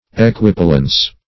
Search Result for " equipollence" : The Collaborative International Dictionary of English v.0.48: Equipollence \E`qui*pol"lence\, Equipollency \E`qui*pol"len*cy\, n. [Cf. F. ['e]quipollence.